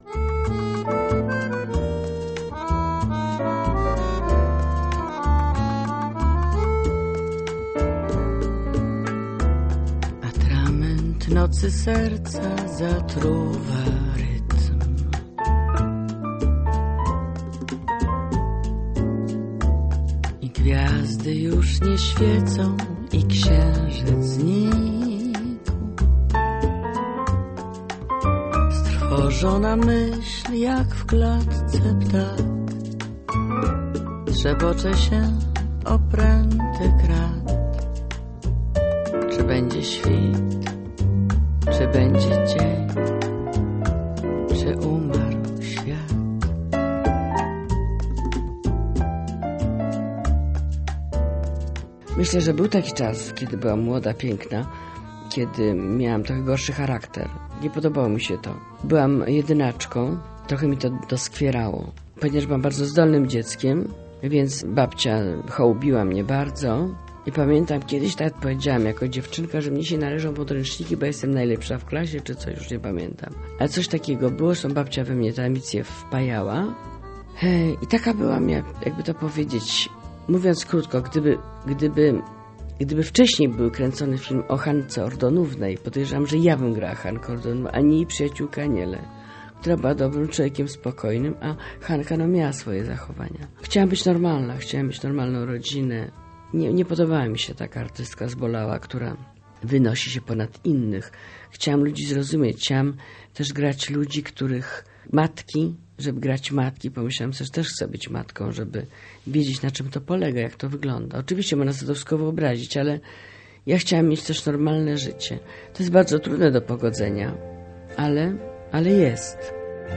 Rok w Poznaniu - reportaż